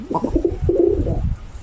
Play Pigeon Sound 2 - SoundBoardGuy
pigeon-sound-2.mp3